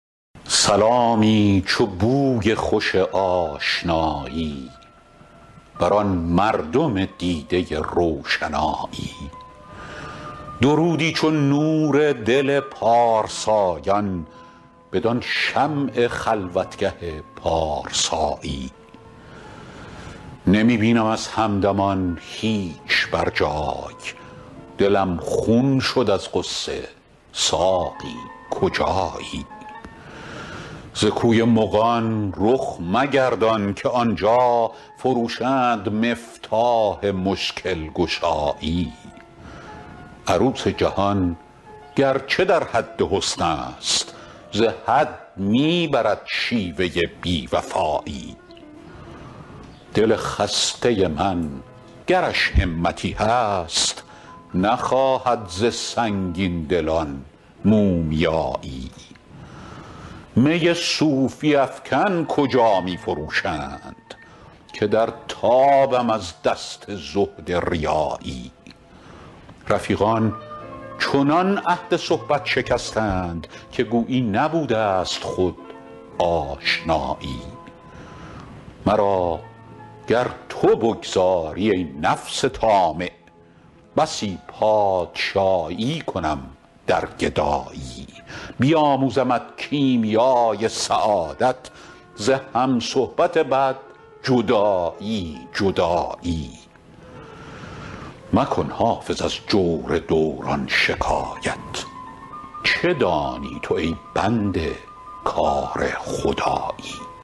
حافظ غزلیات غزل شمارهٔ ۴۹۲ به خوانش فریدون فرح‌اندوز